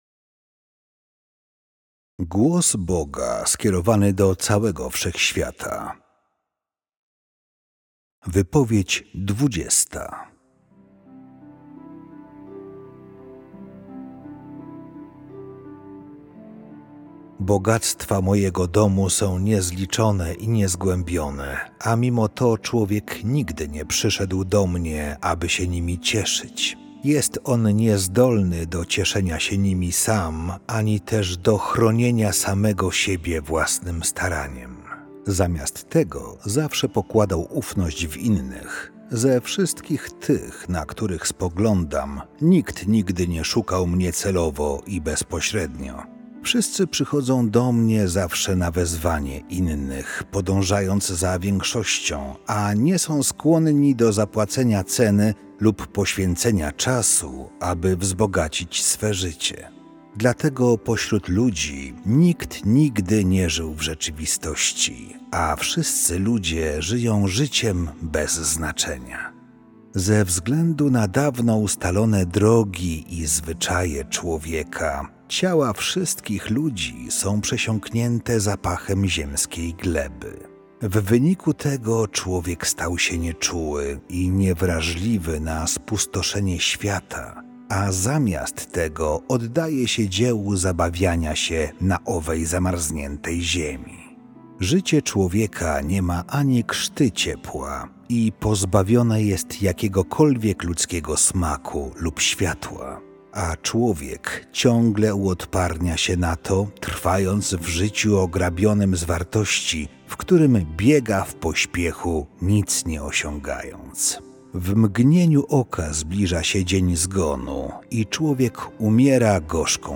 Categories: Readings